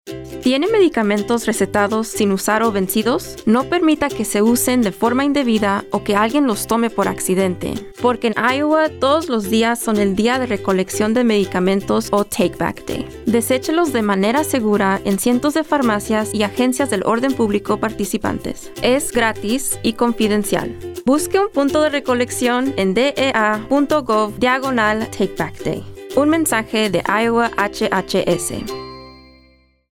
Meds at Home | Radio Spot | FY26 | Spanish